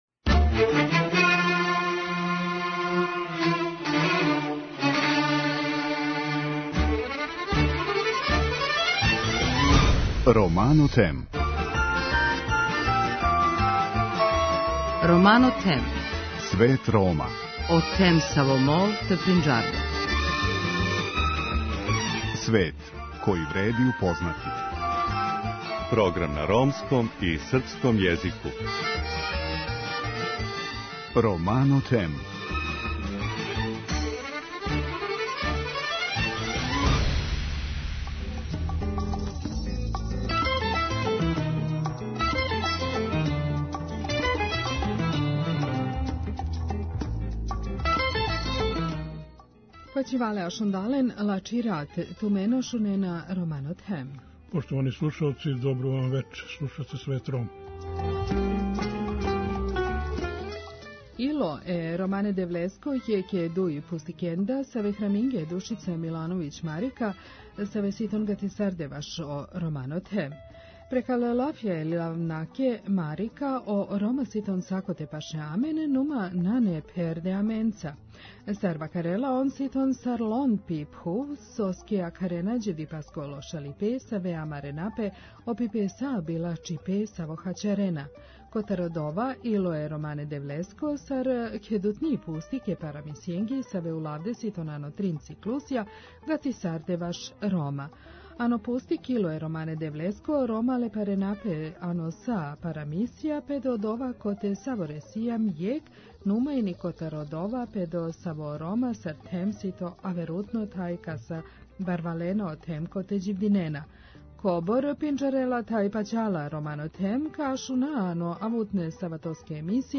И вечерас, прича из збирке „Срце ромског бога“.